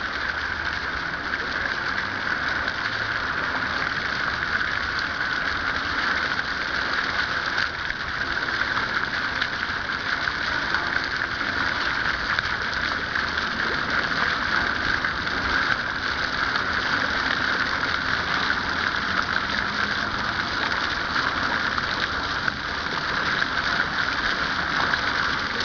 Brunnen-vor-st-ulrich-koenigsbrunn.wav